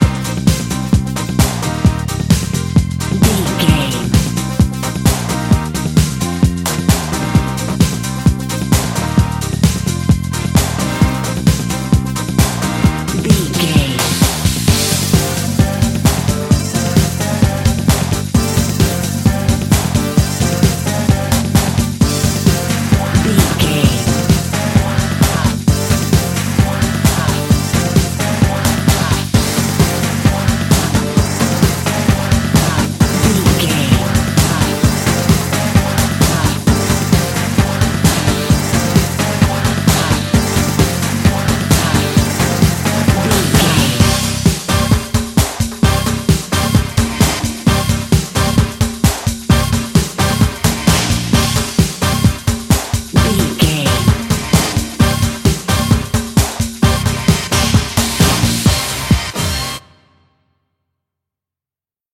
Fast paced
Ionian/Major
synthesiser
drum machine
Eurodance